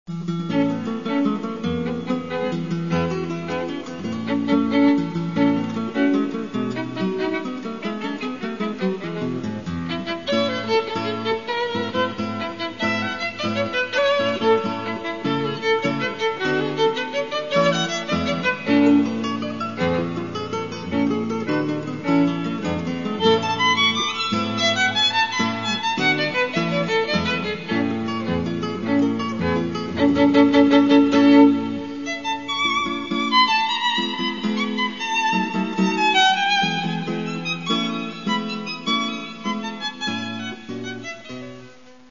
Тому що це – запис живого концерту.